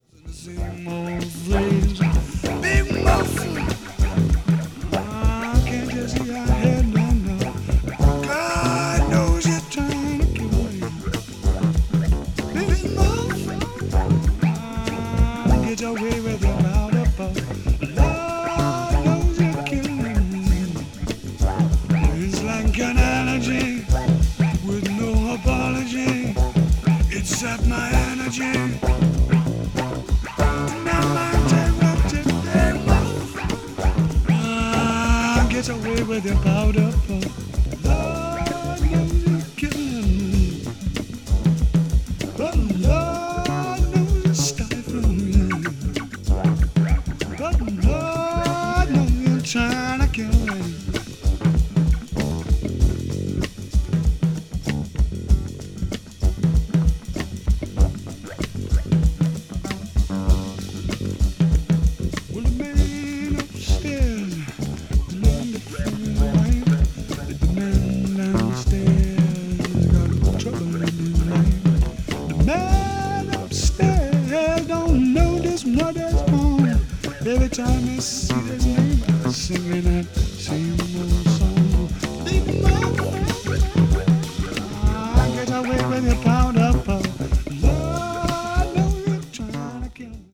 a.o.r.   blues rock   mellow groove   mellow rock